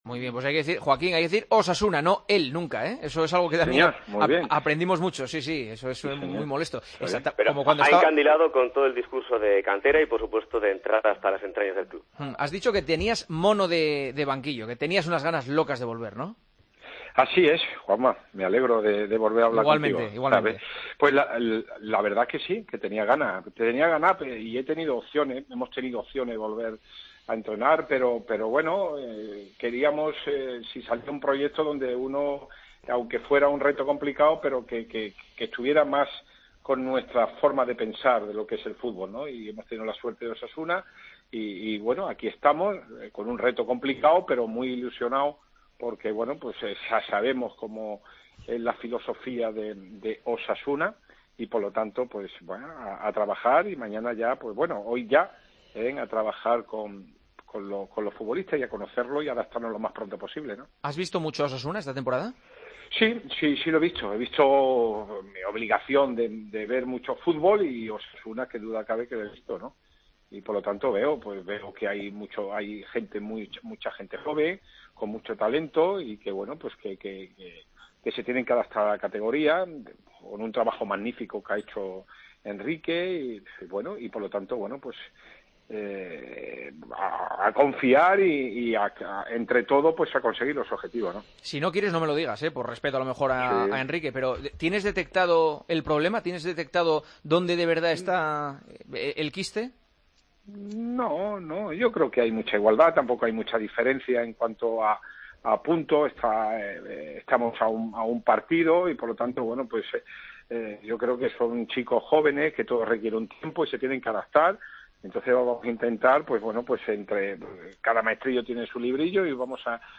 Charlamos con Joaquín Caparrós, recién nombrado entrenador de Osasuna: "Tenía ganas de entrenar, pero quería un proyecto que estuviera más con nuestra forma de pensar de lo que es el fútbol y hemos tenido la suerte de la llamada de Osasuna.